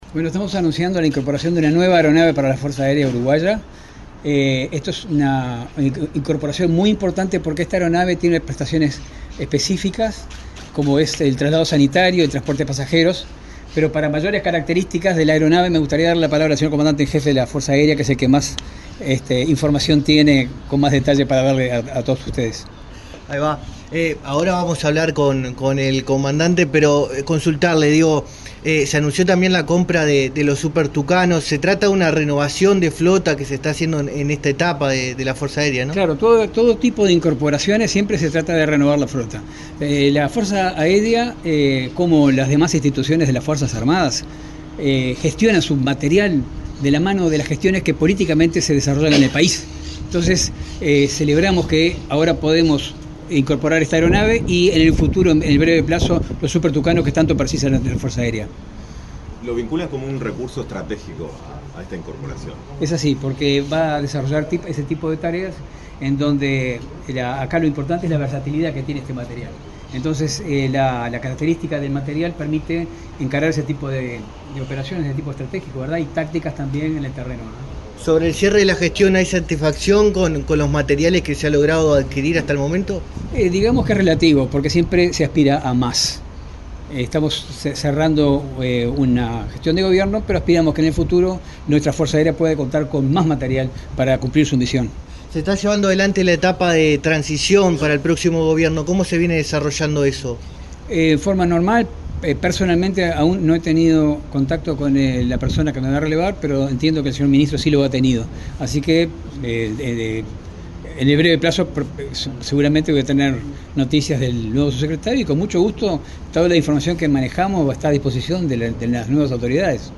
Declaraciones del subsecretario de Defensa Nacional, Marcelo Montaner
Declaraciones del subsecretario de Defensa Nacional, Marcelo Montaner 26/12/2024 Compartir Facebook X Copiar enlace WhatsApp LinkedIn La Fuerza Aérea Uruguaya (FAU) realizó, este jueves 26, la ceremonia de incorporación de un avión Embraer C-120 Brasilia a su flota, asignado al Escuadrón Aéreo n.º 3. El subsecretario de Defensa Nacional, Marcelo Montaner, dialogó con la prensa luego del acto.